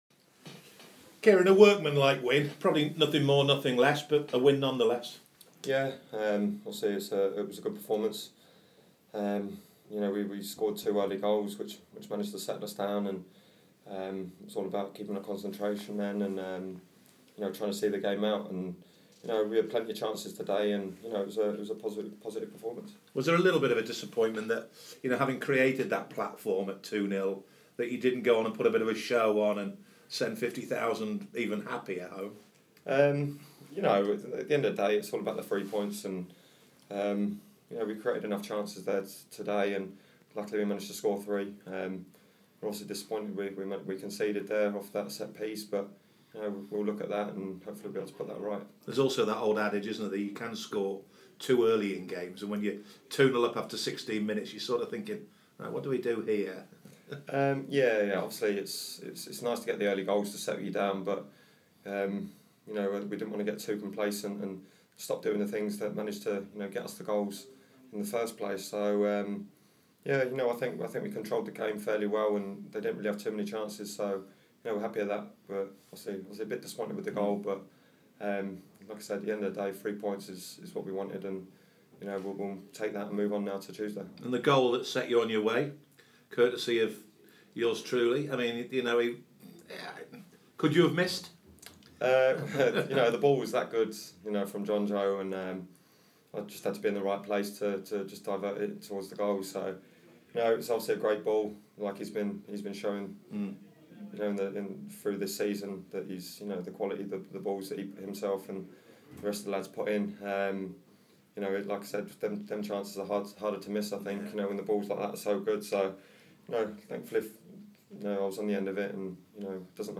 Defender Ciaran Clark spoke to BBC Newcastle after scoring in United's 3-1 win over Brentford.